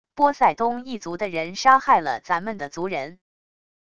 波塞冬一族的人杀害了咱们的族人wav音频生成系统WAV Audio Player